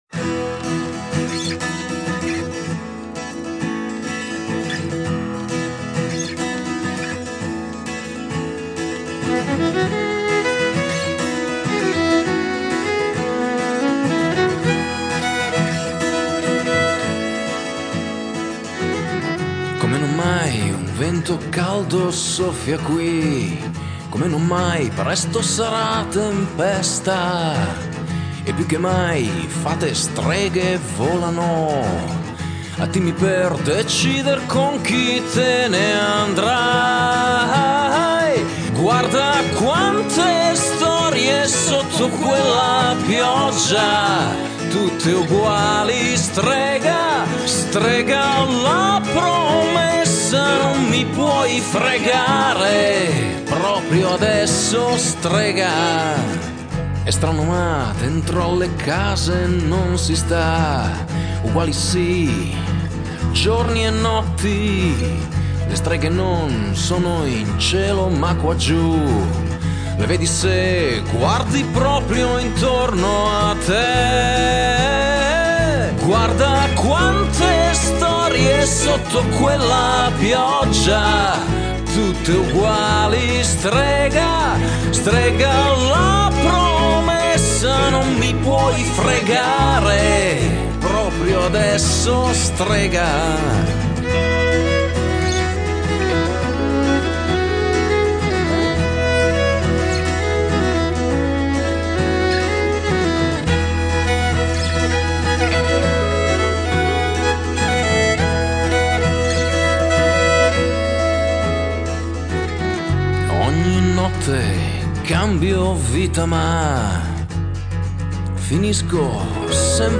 rock band, rock italiano